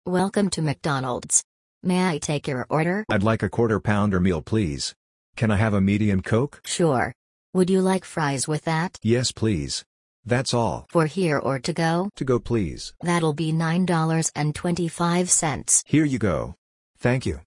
conversation-at-McDonalds.mp3